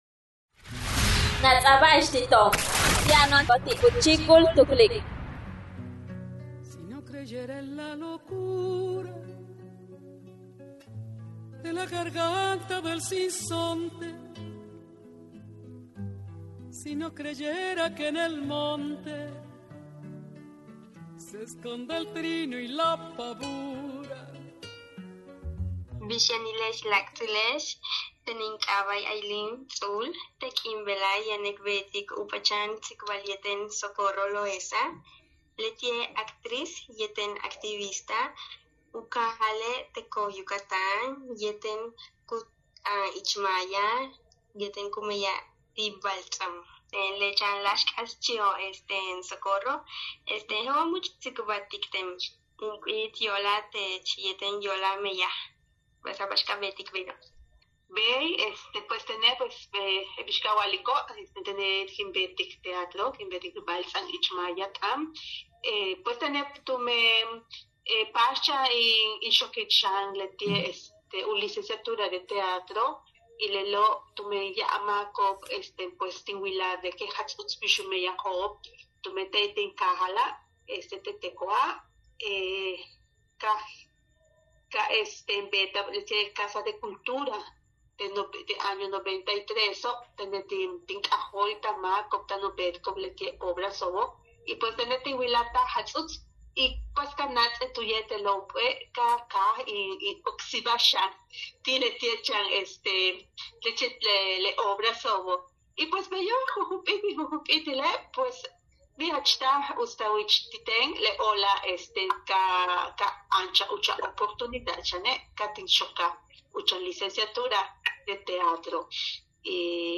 Hacer teatro para visibilizar la riqueza de la cultura maya. Entrevista